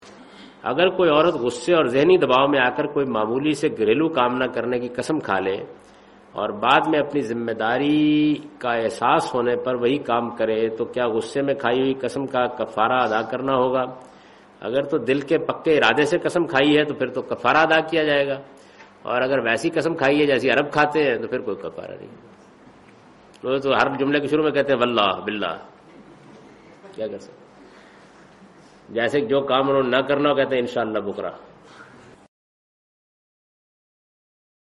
Category: English Subtitled / Questions_Answers /
Javed Ahmad Ghamidi responds to the question ' Is atonement mandatory if oaths sworn in extreme conditions of pressure or anger are broken'?